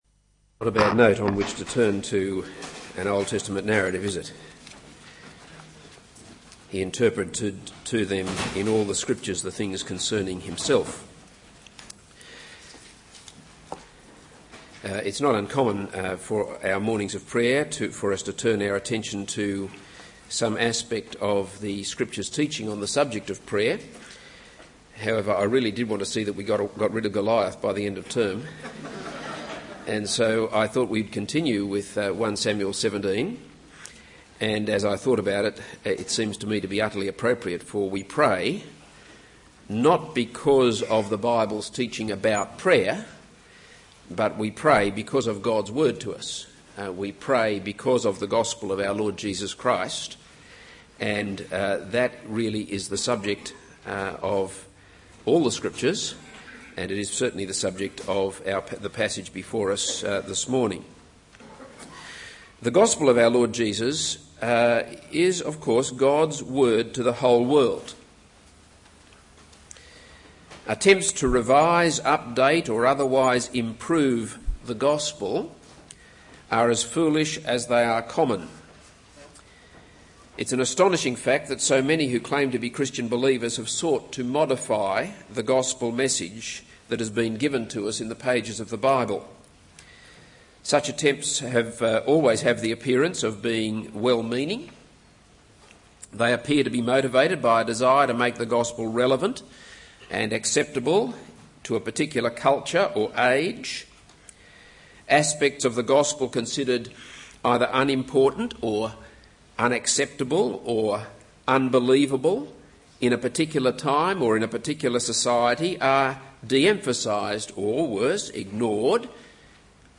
This is a sermon on 1 Samuel 17:31-37.